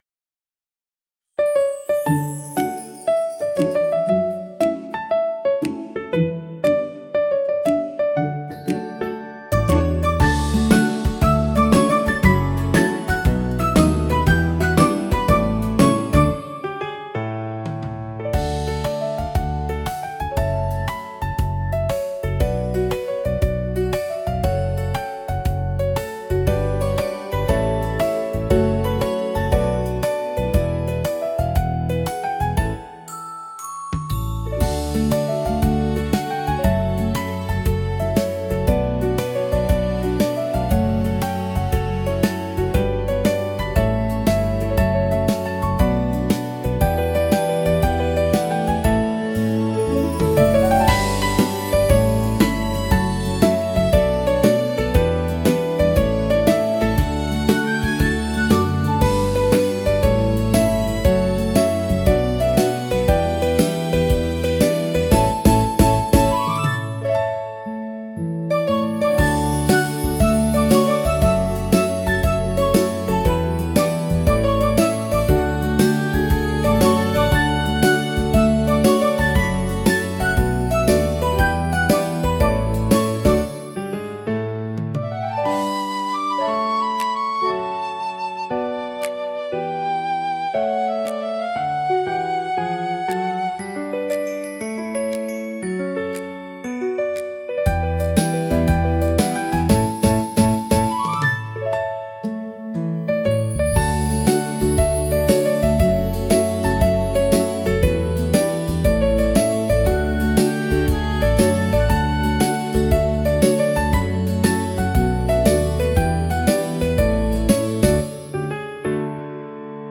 明るさと元気さで、場の雰囲気を一気に盛り上げ、聴く人にポジティブな感情を喚起します。